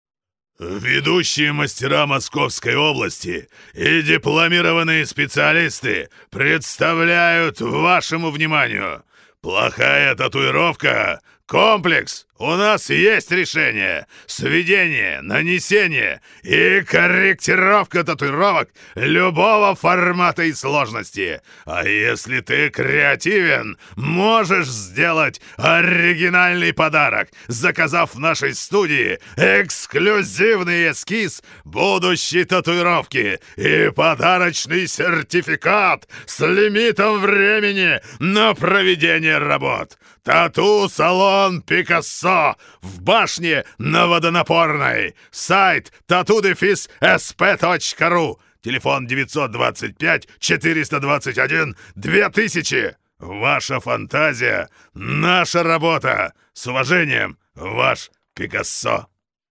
Тракт: Микрофон AT 4060 - Преамп Universal Audio 710 - symetrix 528e - Echo audiofire8